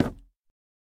Minecraft Version Minecraft Version 1.21.5 Latest Release | Latest Snapshot 1.21.5 / assets / minecraft / sounds / block / chiseled_bookshelf / break4.ogg Compare With Compare With Latest Release | Latest Snapshot
break4.ogg